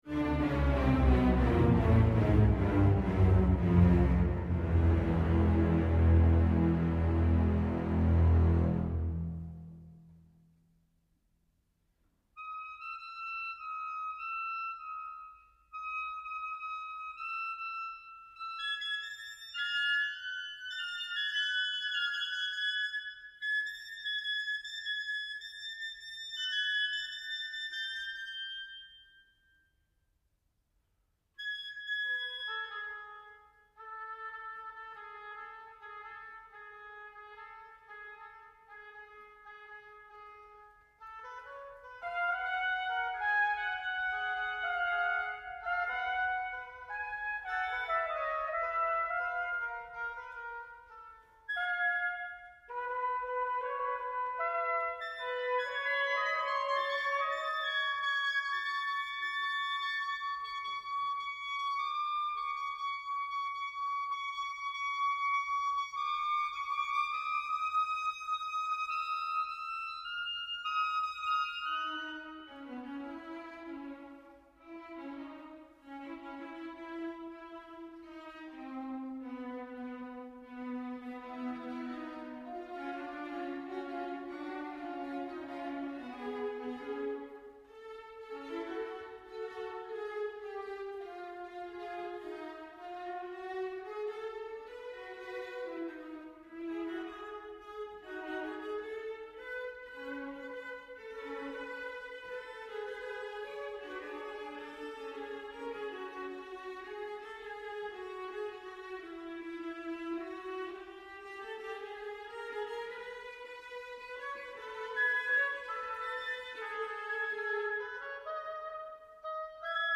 large orchestra and solo piano